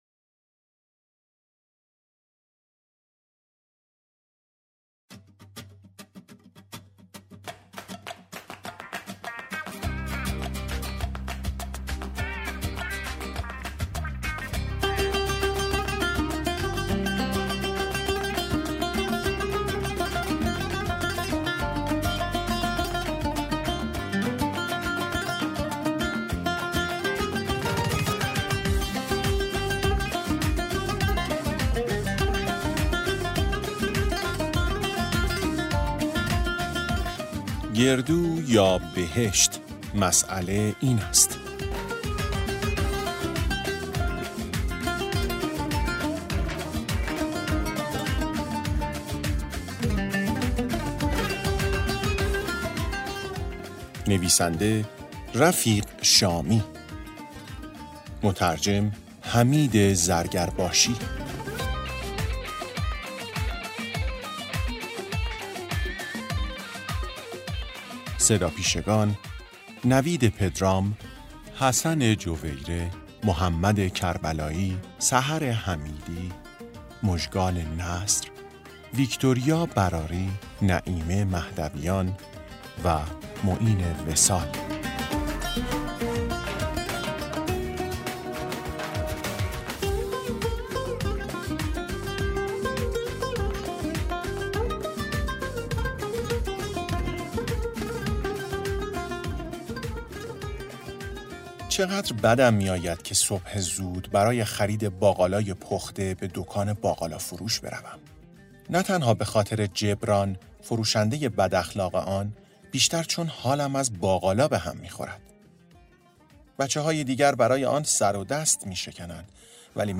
شب هنگام- داستان کوتاه